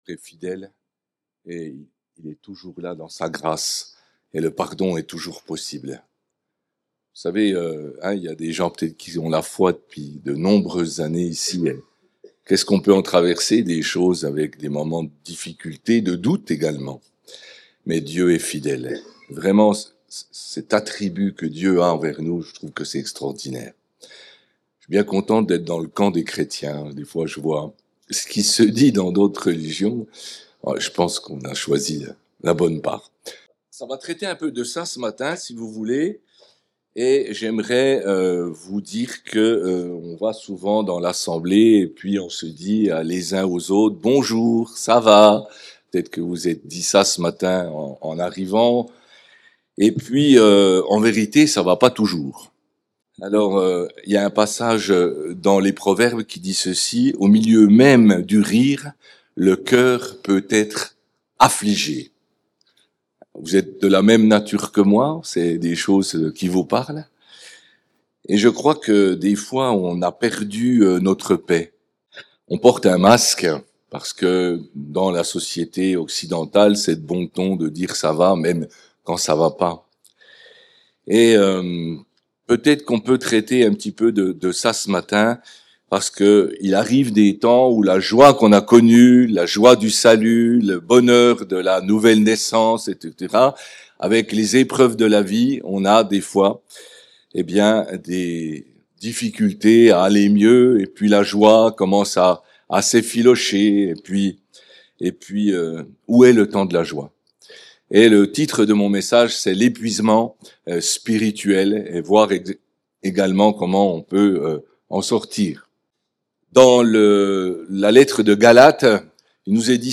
Culte hebdomadaire